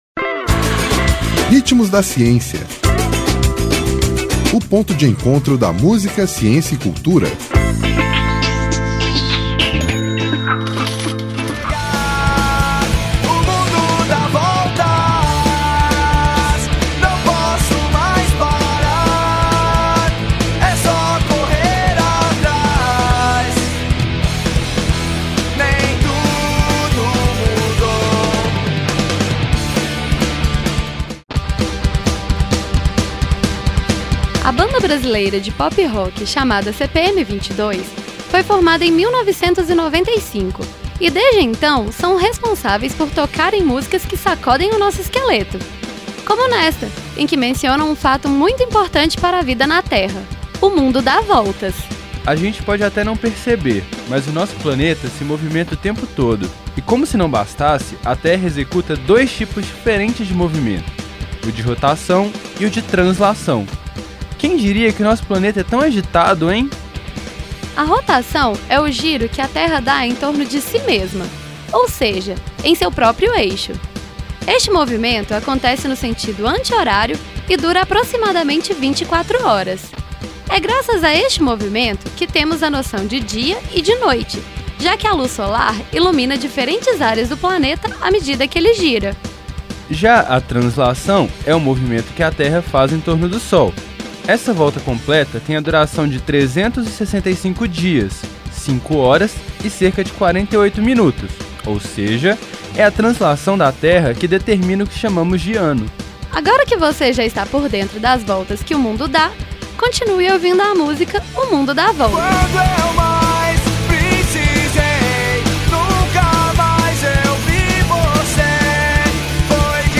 Nome da música: O mundo dá voltas